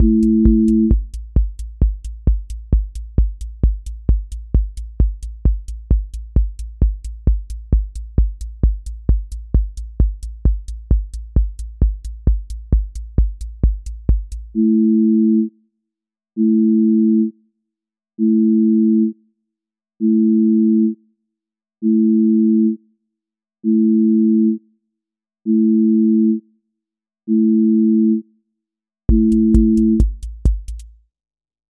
QA Listening Test trance Template: trance_euphoria
QA Probe trance 30s with euphoric build and drop, pumping offbeat bass, and a breakdown into wide pads
• voice_kick_808
• voice_hat_rimshot
• fx_space_haze_light
• tone_brittle_edge